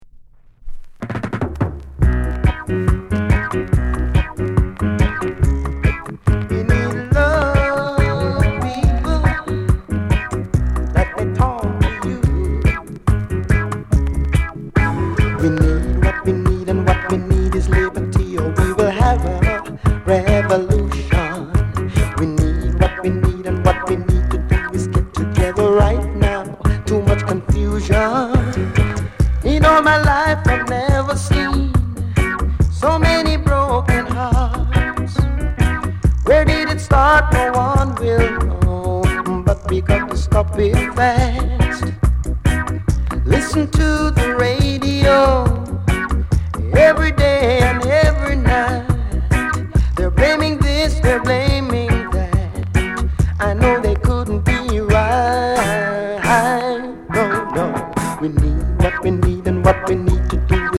NICE VOCAL